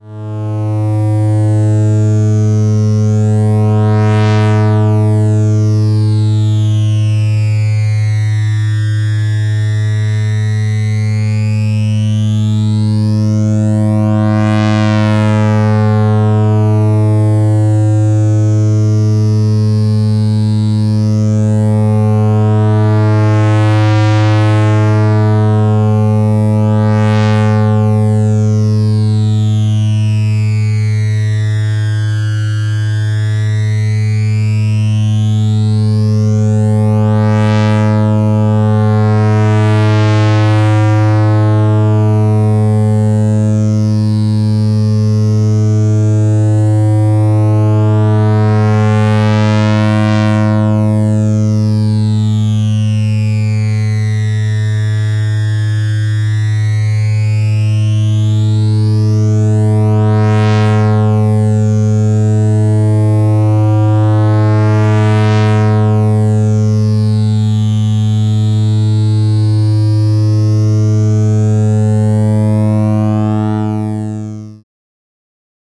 This module is a waveshaper that uses several PWM circuits (comparators) in parallel to create "staircase" style waveshapes.
DEMO 3. This the 8 step output with a triangle wave input and a pair of LFOs modulating the HI/LO thresholds.